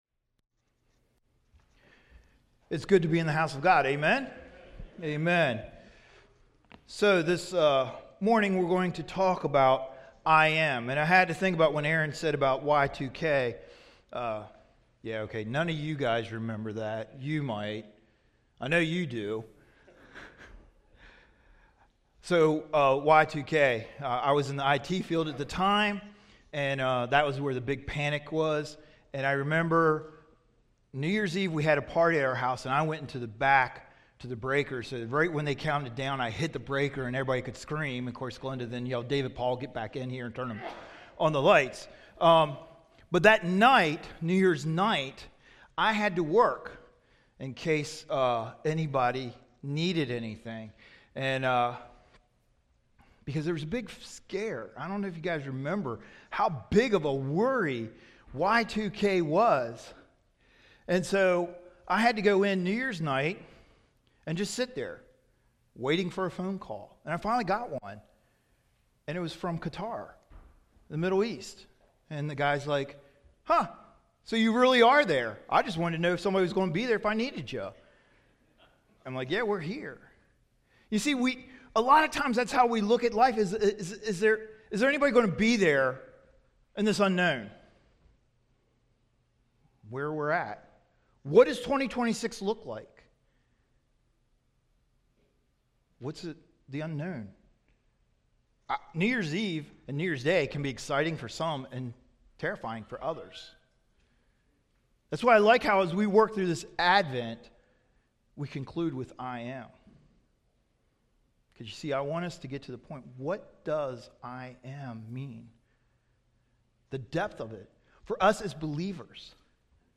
Tagged with 2nd Service Audio (MP3) Previous Everlasting Father Next The Universe as We Know It...The Majesty of God 0 Comments Add a Comment Cancel Your email address will not be published.